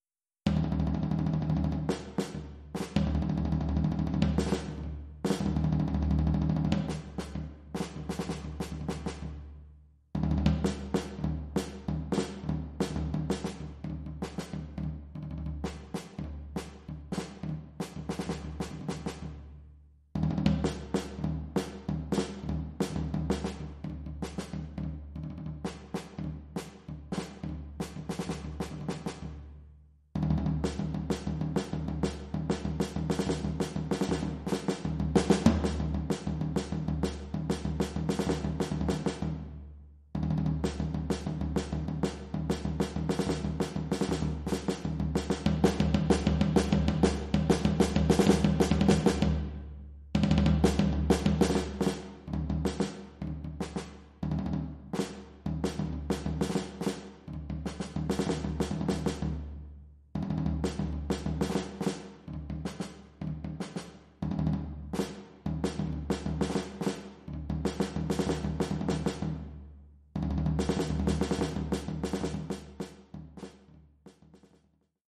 Oeuvre pour tambour seul.